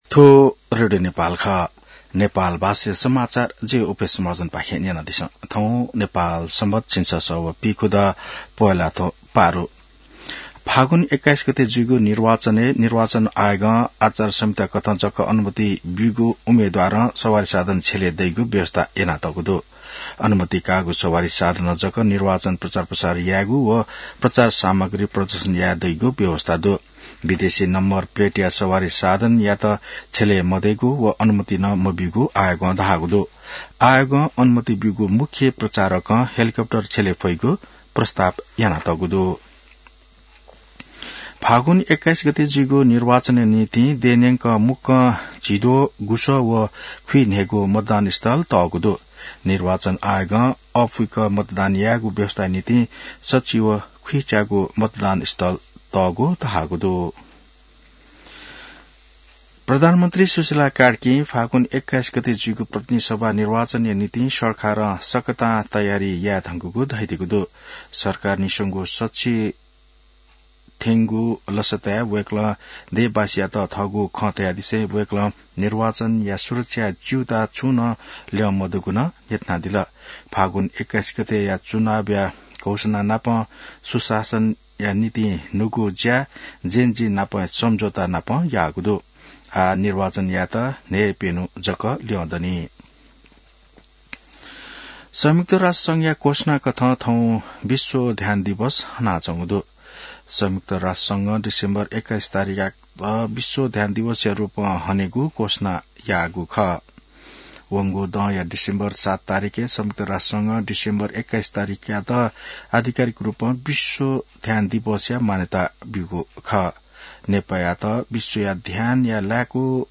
नेपाल भाषामा समाचार : ६ पुष , २०८२